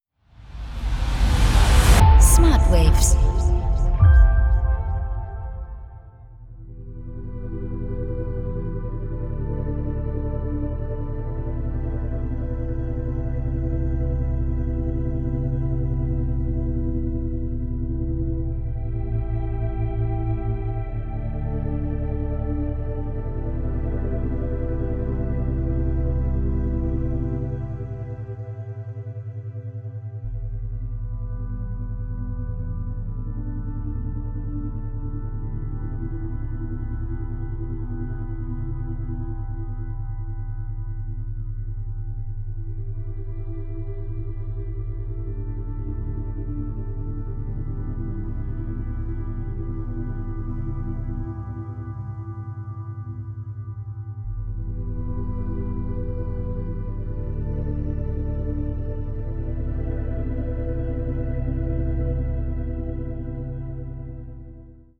Alpha Wellen Musik
| Entspannung | 10 hZ